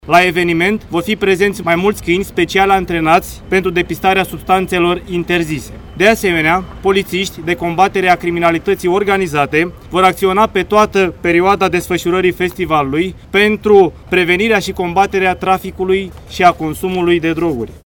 Agentul de poliţie